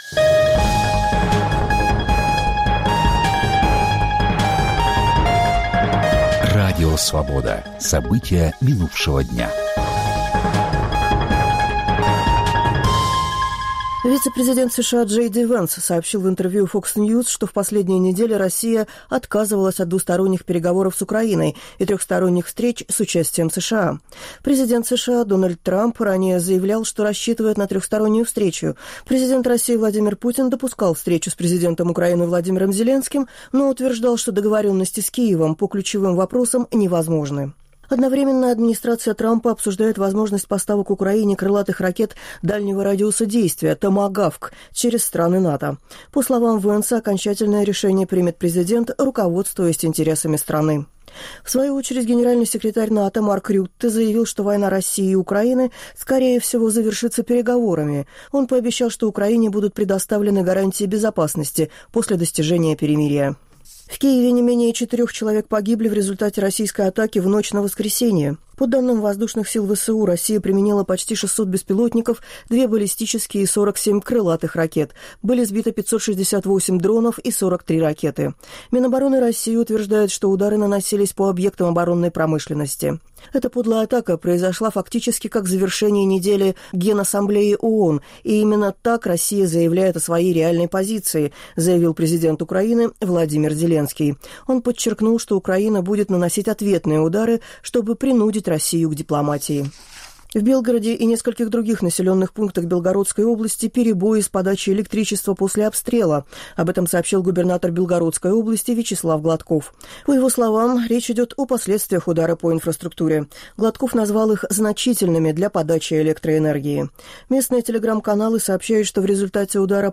Аудионовости
Новости Радио Свобода: итоговый выпуск